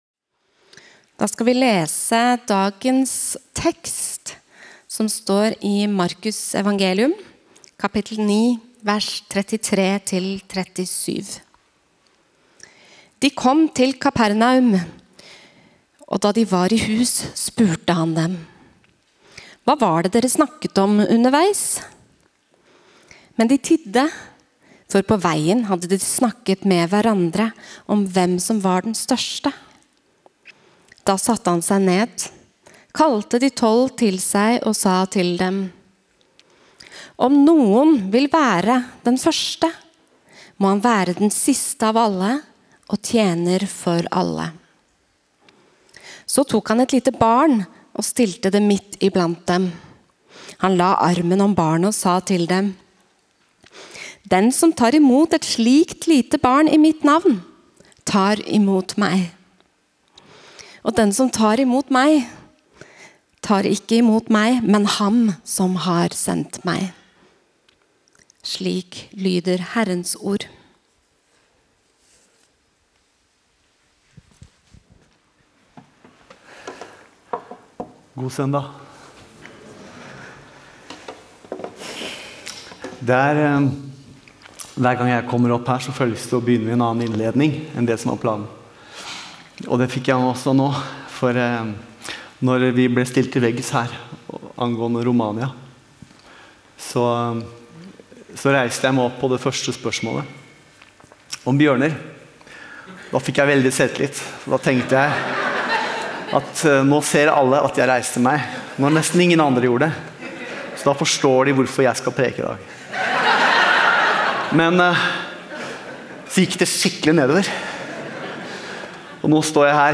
Forkynnelse og opptak fra Oslo misjonskirke Betlehem